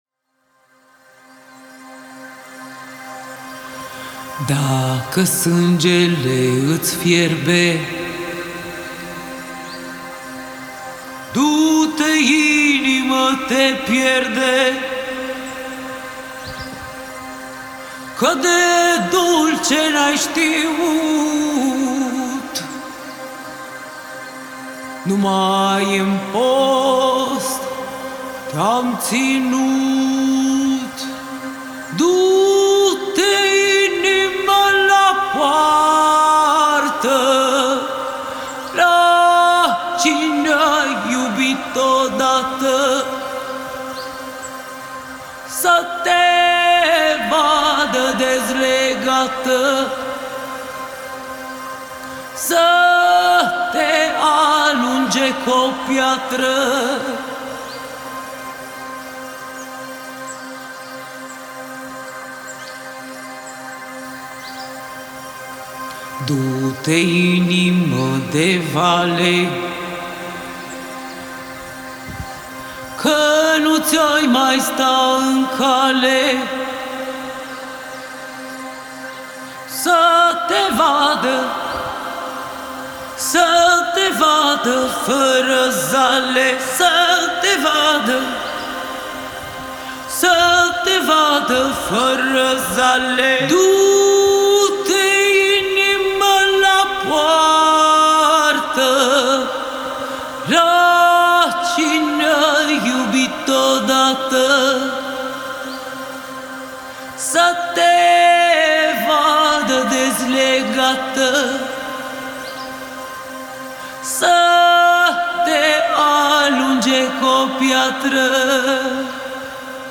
Genre:Cinematic
デモサウンドはコチラ↓
51 Vocal Loops
45 Adlib Vocal Loops
31 Vocal Adlibs